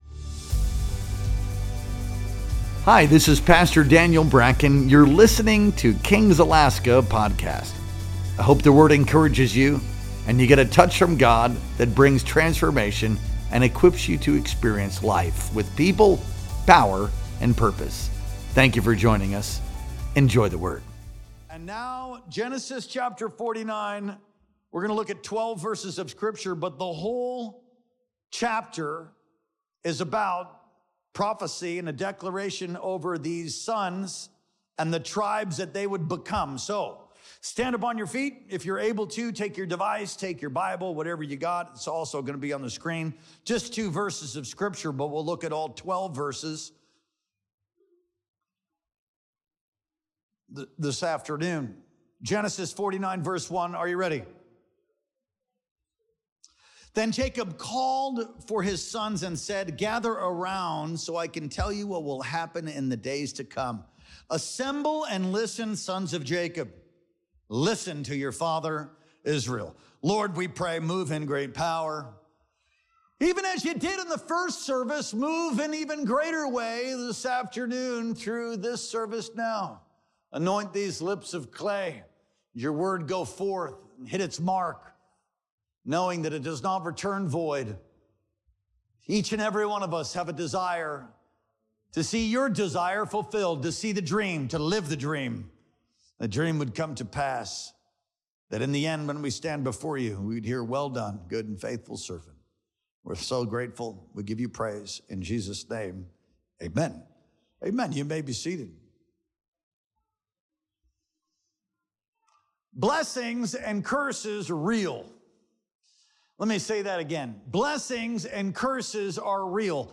Our Sunday Worship Experience streamed live on July 6th, 2025.